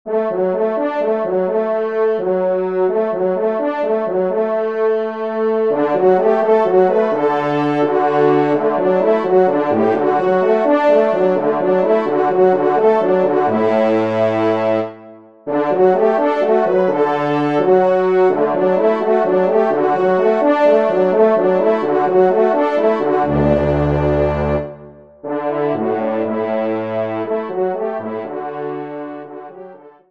Trompe Basse